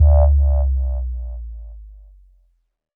Bass Power Off 4.wav